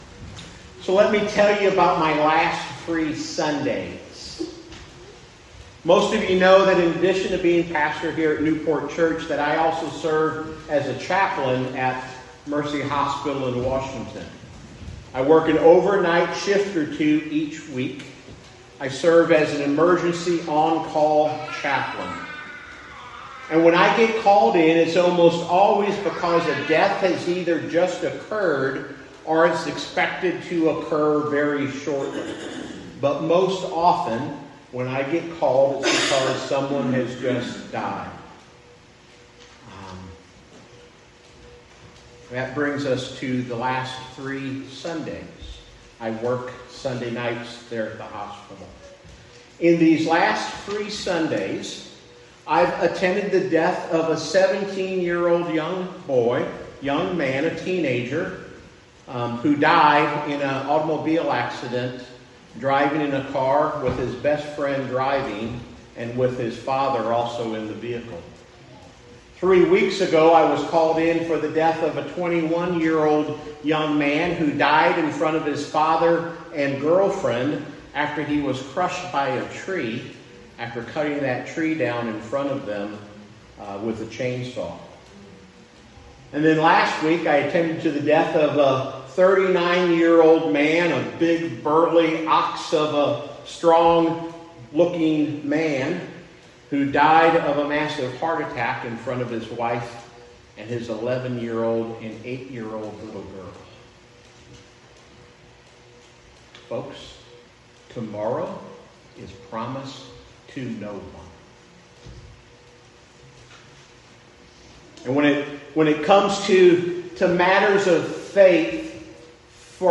Sermon, New Port Presbyterian Church